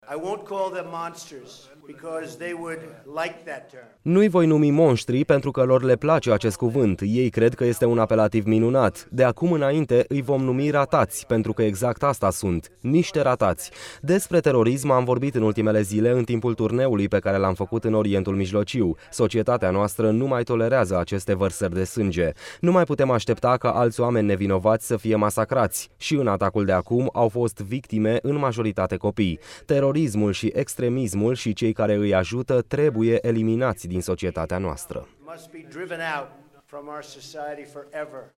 Aflat la Bethlehem, președintele Statelor Unite ale Americii le-a trasmis condoleanțe familiilor care și-au pierdut apropiații în atacul de la Manchester.
23mai-12-Trump-tradus-de-acum-inainte-ii-voi-numi-ratati-.mp3